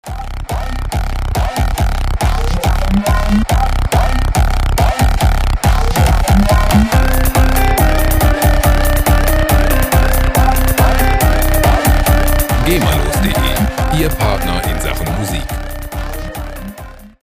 gema-freie Loops aus der Rubrik "Trance"
Musikstil: Hard Trance
Tempo: 140 bpm